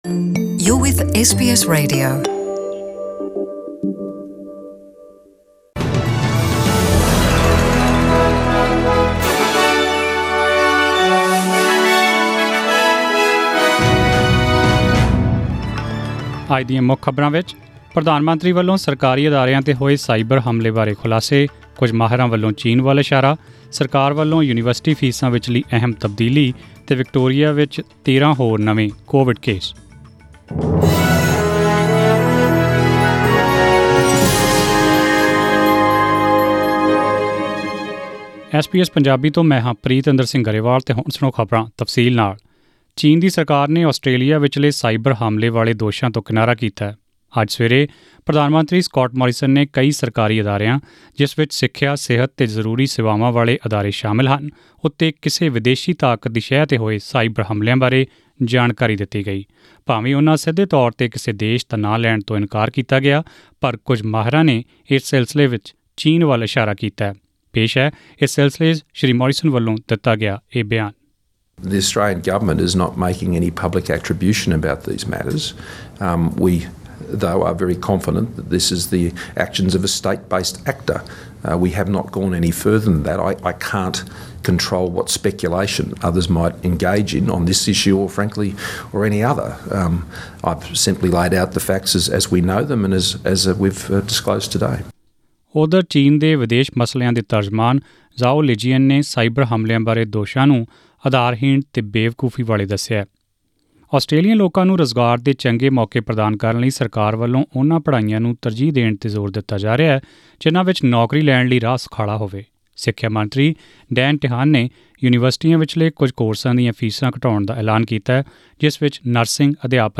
To hear the full news bulletin, click on the audio player above.